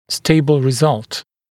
[‘steɪbl rɪ’zʌlt][‘стэйбл ри’залт]стабильный результат